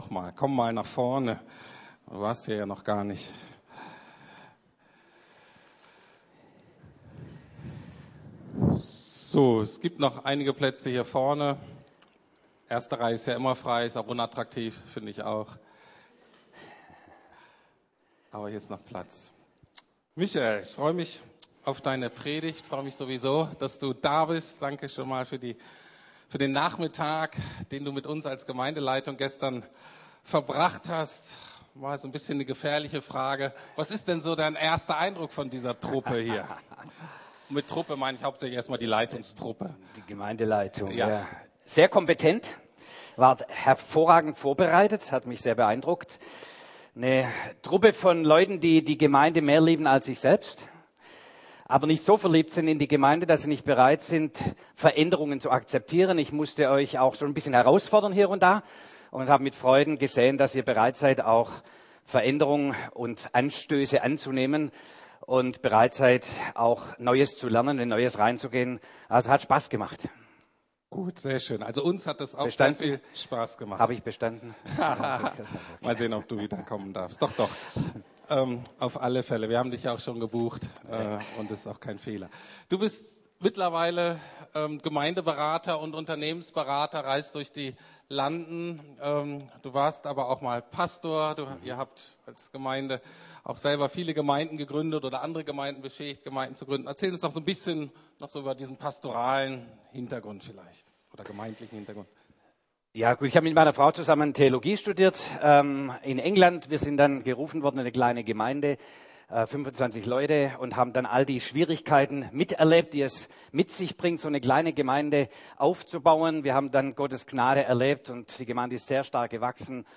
Gottes Strategie und Verheißung in Zeiten des Umbruchs! ~ Predigten der LUKAS GEMEINDE Podcast